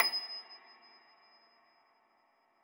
53k-pno27-D6.aif